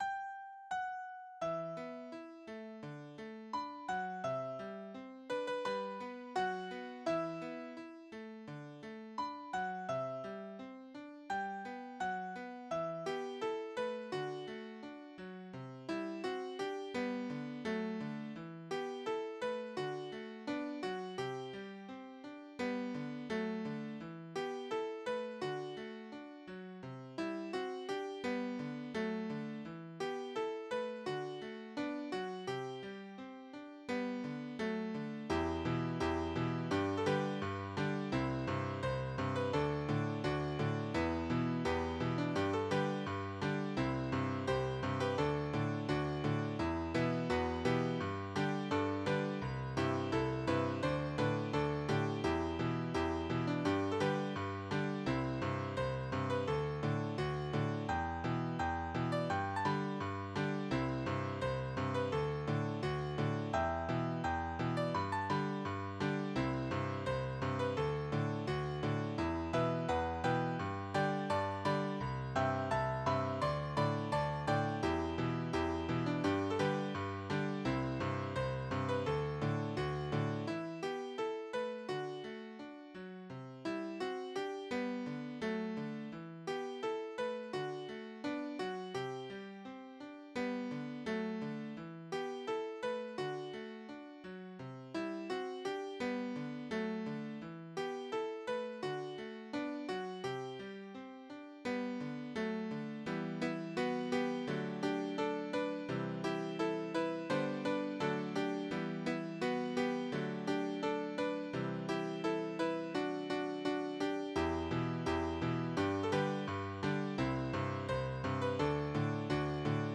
moje ženeral midi kolekce
piano